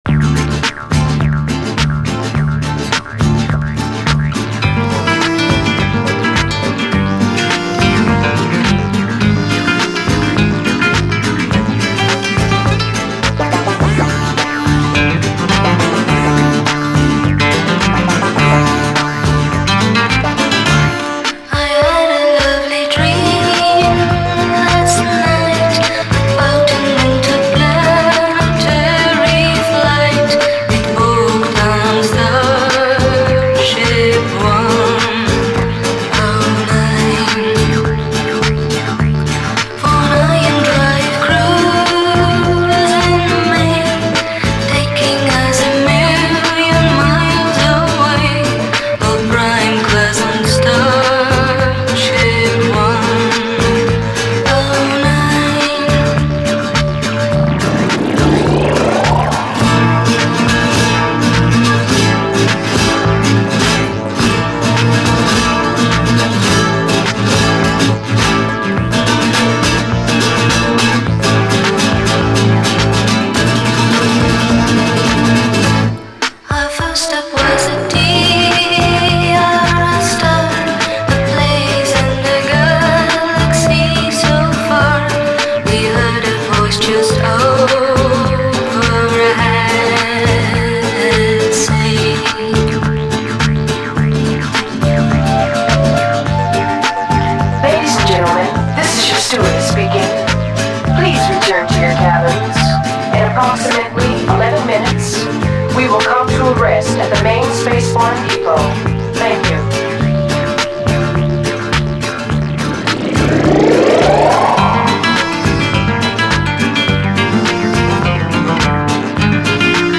DISCO, 70's ROCK, ROCK, 7INCH